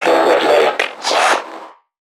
NPC_Creatures_Vocalisations_Infected [85].wav